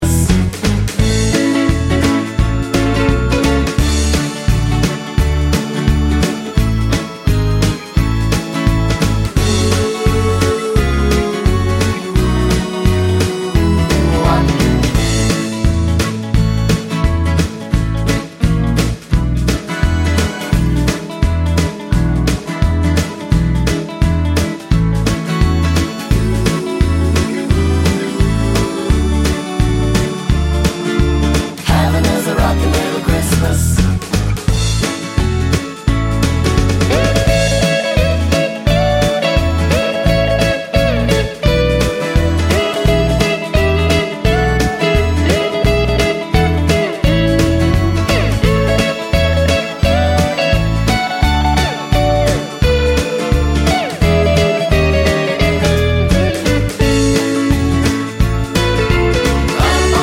no Backing Vocals Christmas 2:41 Buy £1.50